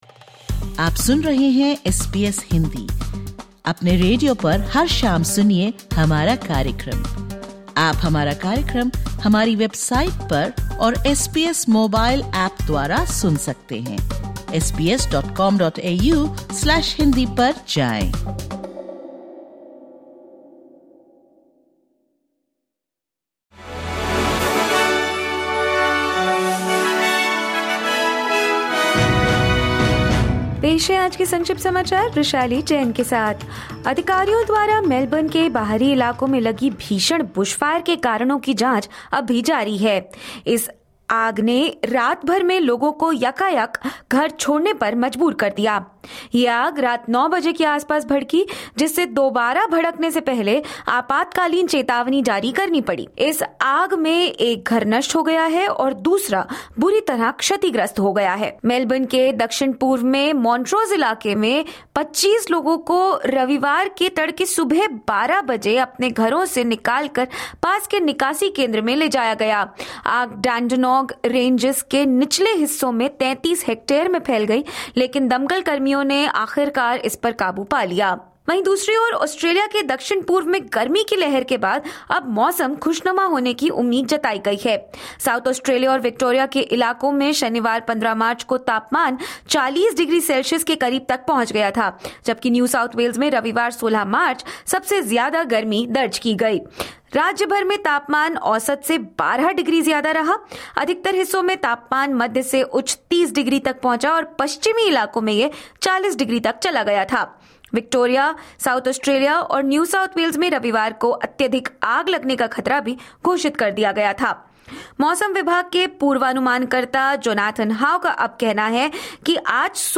Listen to the top News of 16/03/2025 from Australia in Hindi.